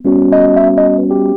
07 Rhodes 27.wav